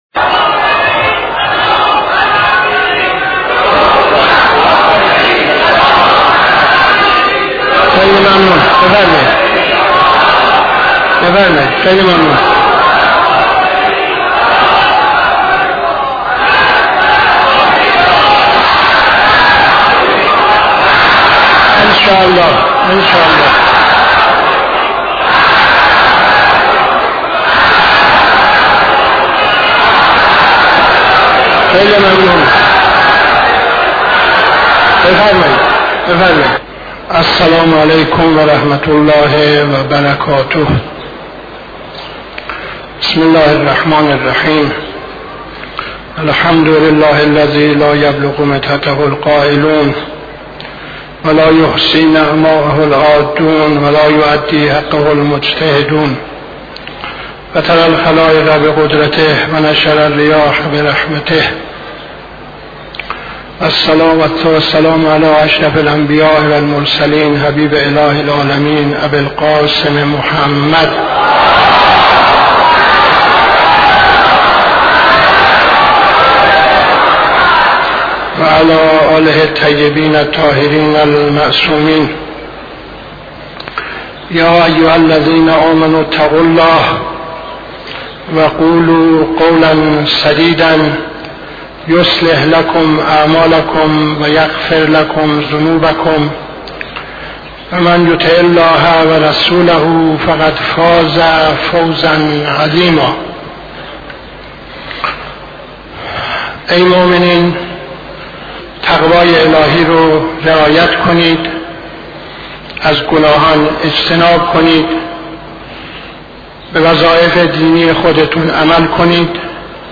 خطبه اول نماز جمعه 19-01-73